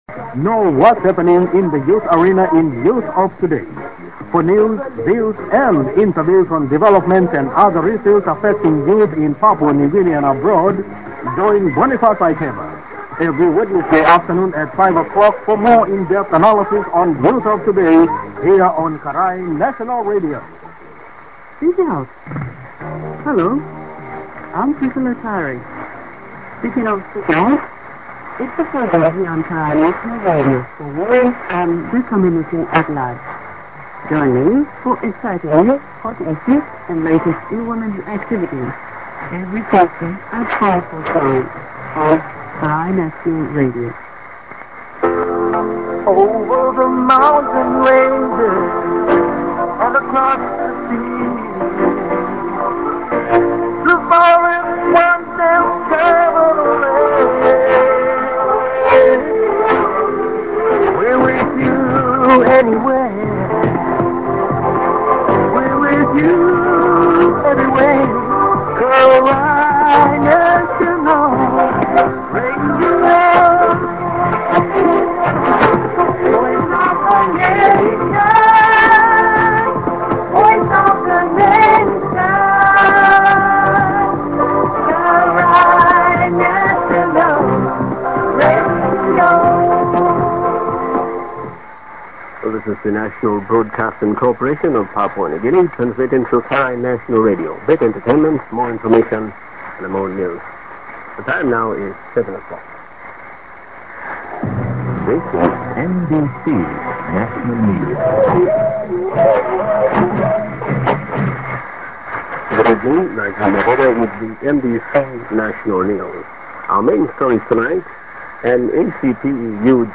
Mid. ->ID(man)->ID:NBC:National Broad. Corp. National Radio(man)->ID:NBC(man)->SJ->　CQ9803